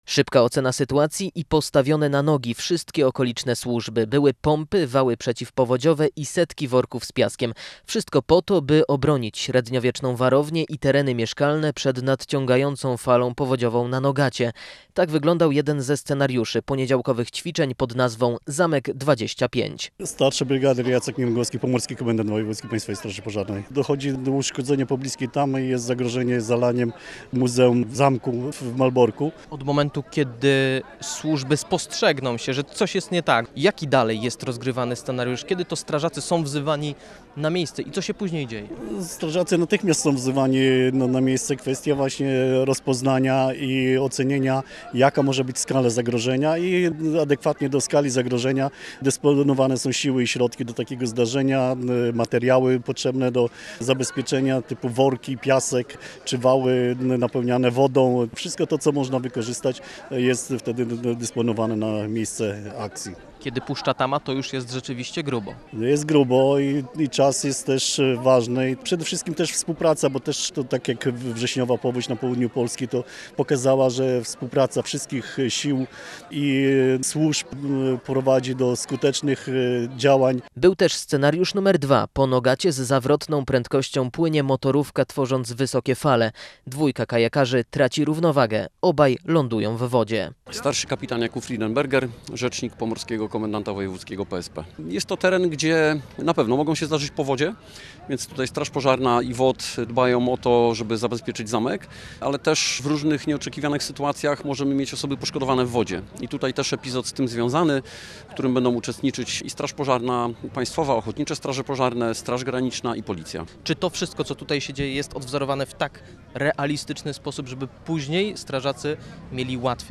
Jak wyjaśnia pomorski komendant wojewódzki PSP st. bryg. Jacek Niewęgłowski, do ćwiczeń zadysponowano kilkudziesięciu strażaków
Posłuchaj materiału naszego reportera: https